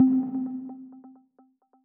pause-continue-hover.wav